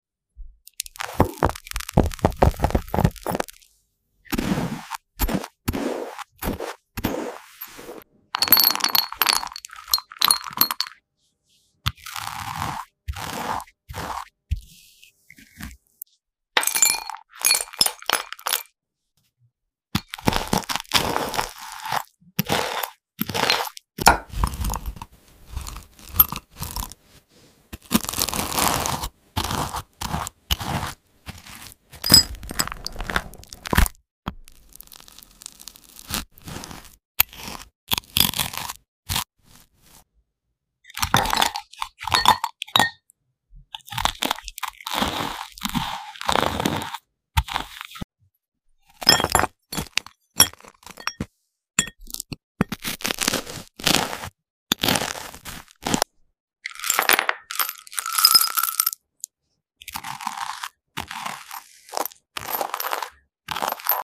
Spreading Ice Cream On Toast Sound Effects Free Download
Upload By Satisfying Slices ASMR